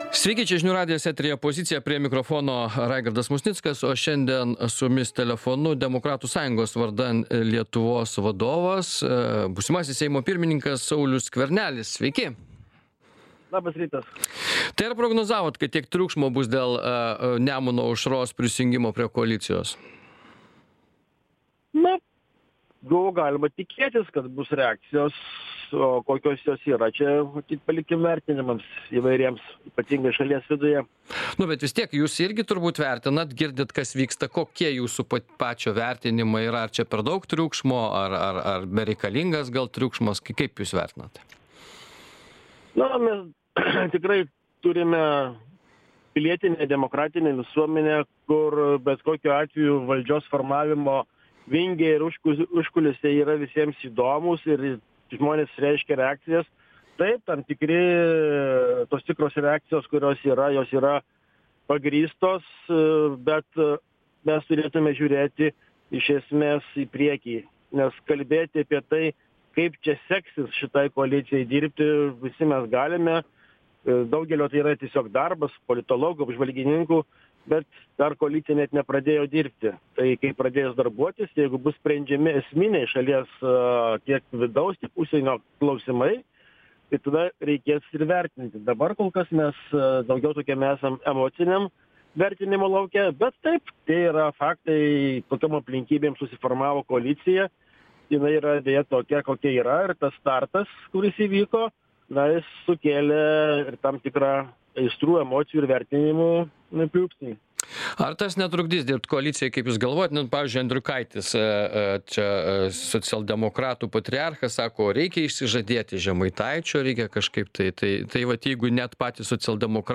Laidoje dalyvauja Demokratų sąjungos „Vardan Lietuvos“ pirmininkas Saulius Skvernelis.